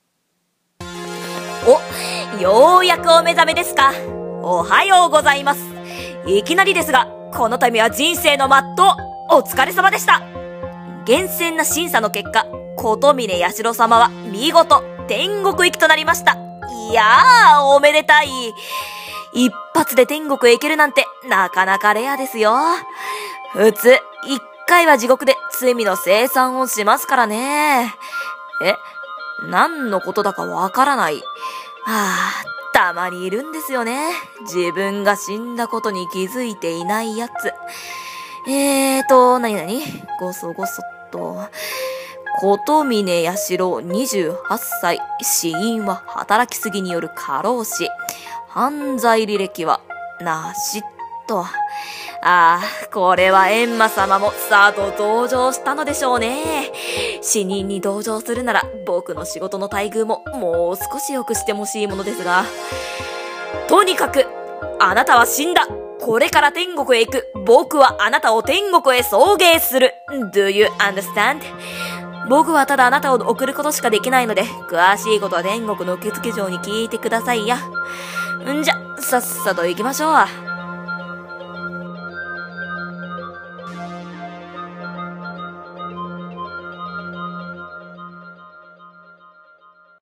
◉1人声劇『送迎屋』台本